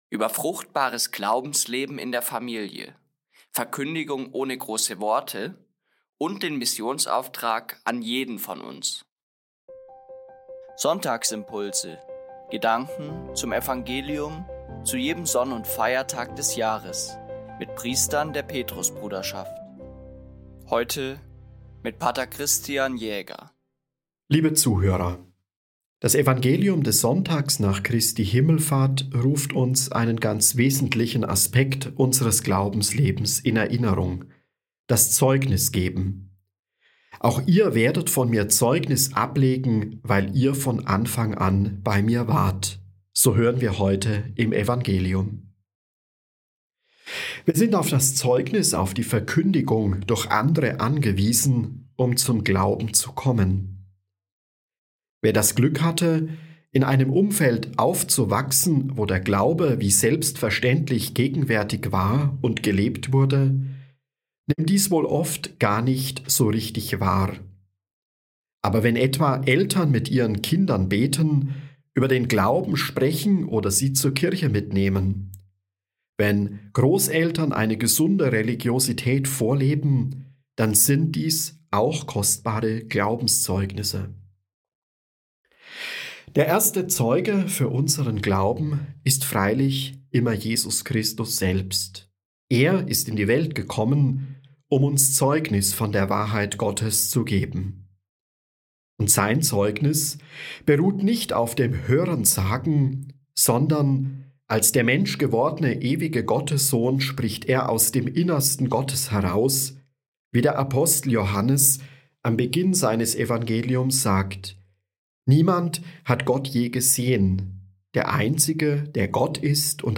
Gedanken zum Evangelium – für jeden Sonn- und Feiertag des Jahres mit Priestern der Petrusbruderschaft